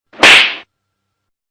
Slappy Sound
Slap_Sound_Effect_Free_getmp3.pro_.mp3